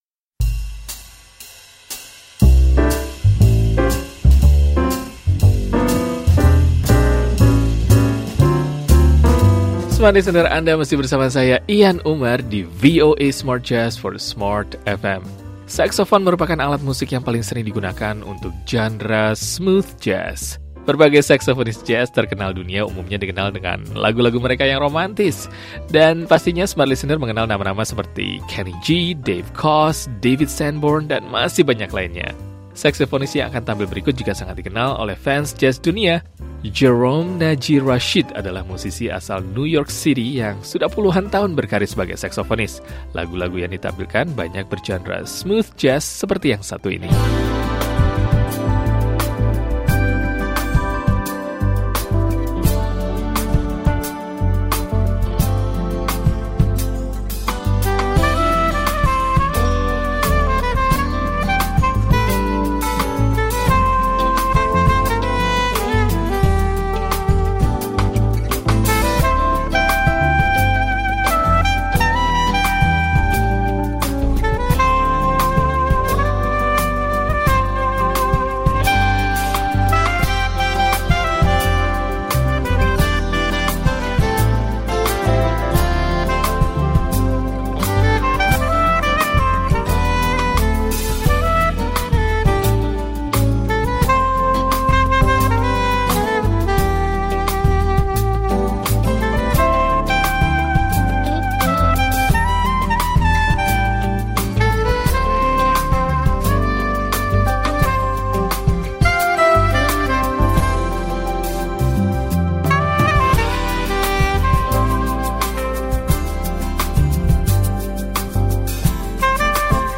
VOA Smart Jazz ditutup dengan penampilan Seth MacFarlene.